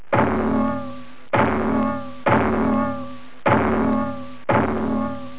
Sound effects wavs
Bangs
bangs.wav